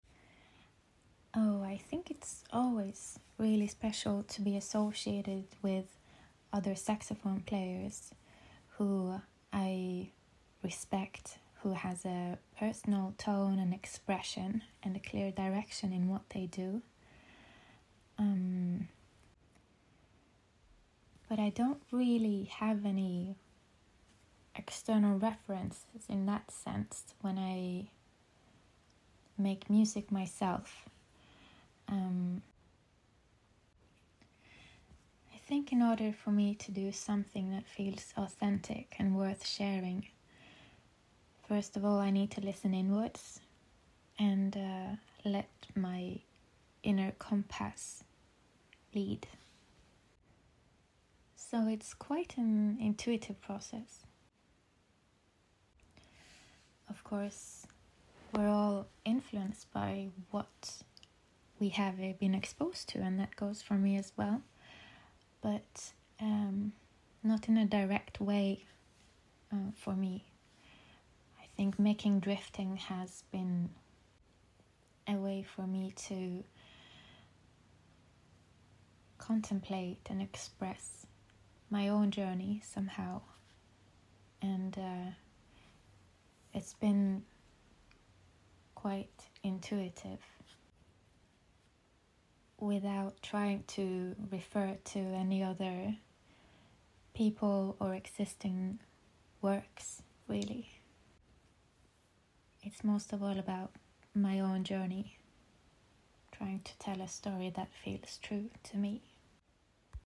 Tenorsaxofon
Piano
Cello